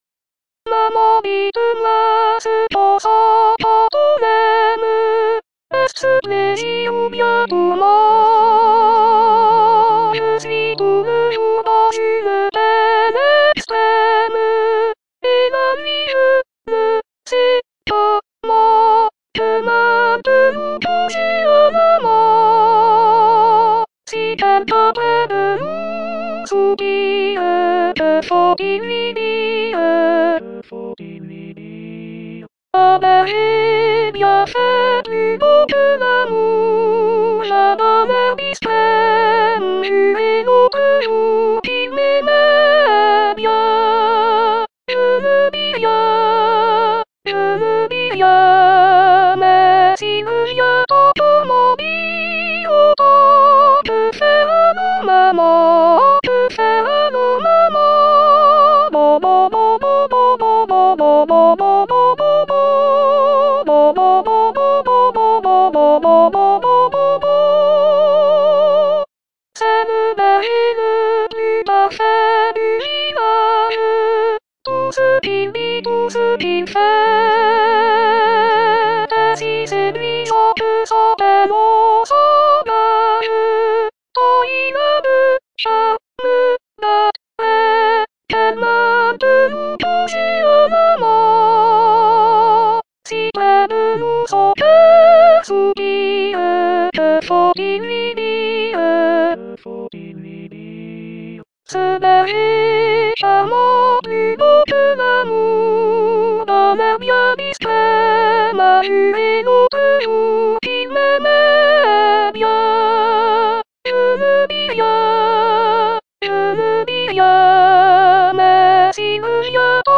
Weckerlin-02-Soprano.mp3